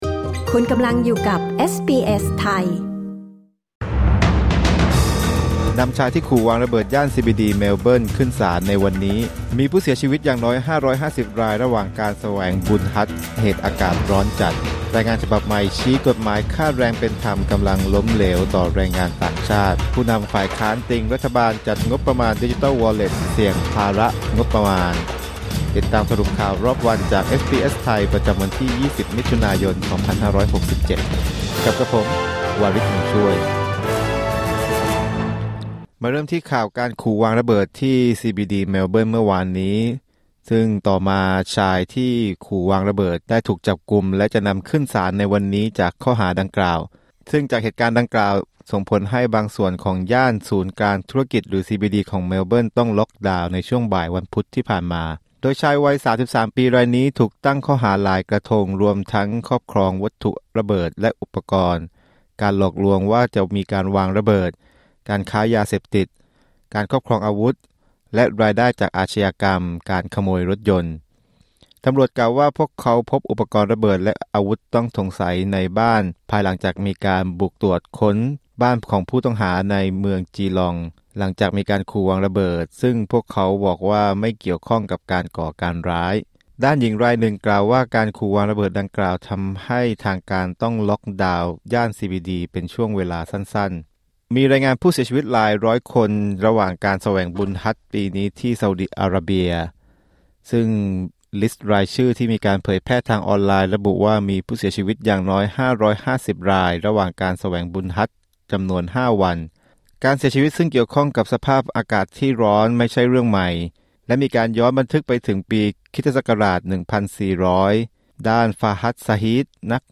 สรุปข่าวรอบวัน 20 มิถุนายน 2567